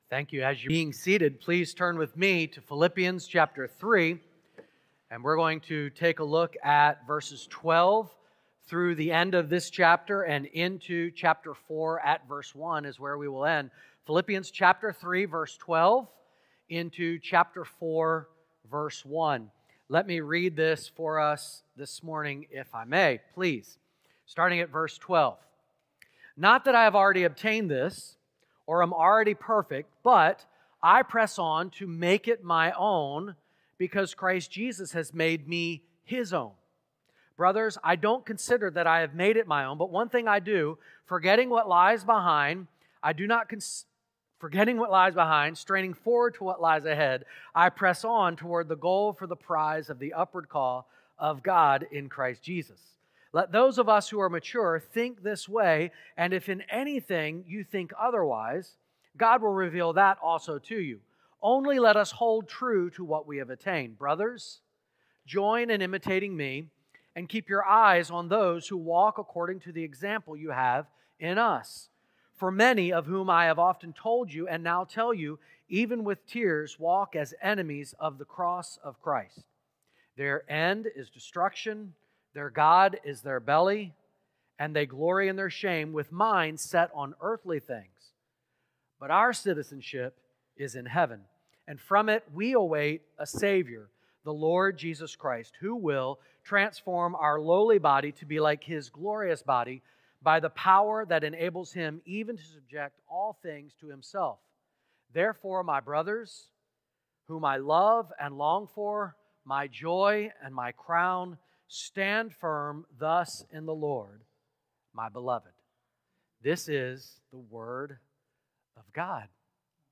Sermons | Great Commission Baptist Church